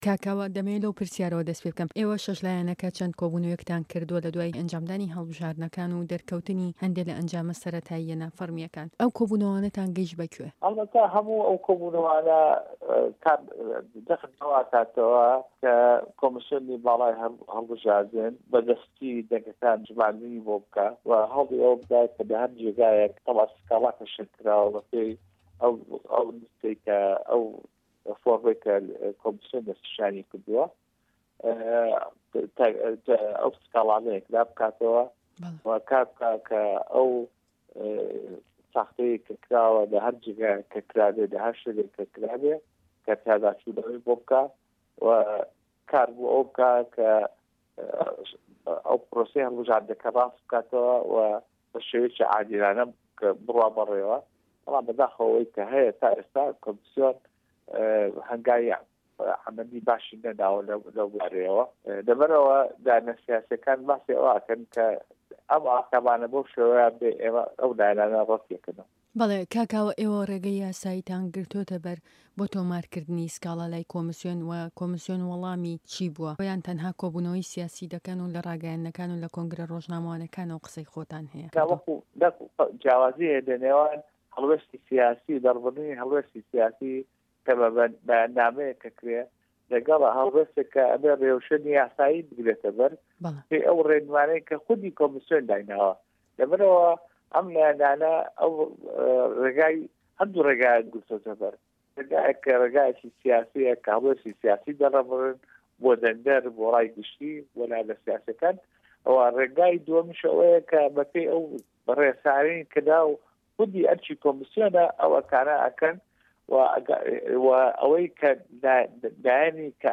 ده‌قی وتووێژه‌كه‌ی به‌شی كوردی ده‌نگی ئه‌مریكا له‌گه‌ڵ د.كاوه‌ مه‌حمود